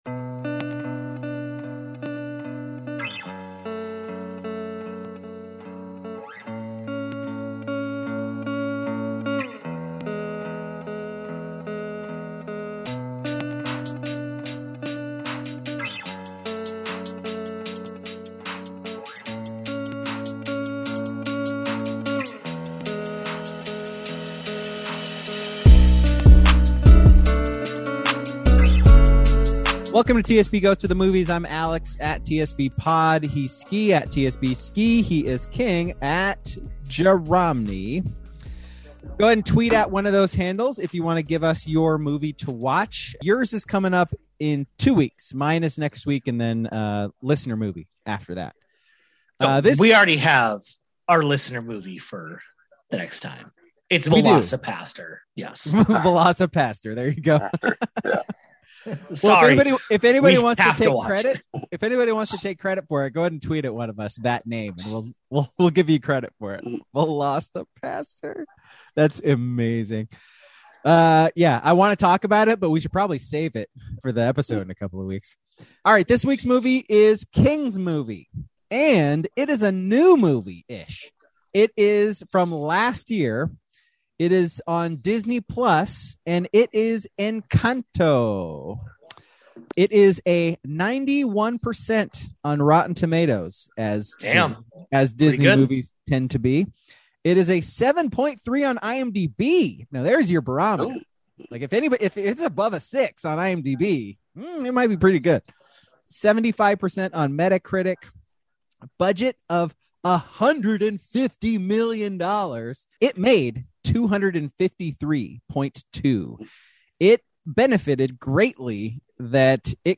Ever want to hear three nearly middle aged white guys talk about a kids movie set in Colombia?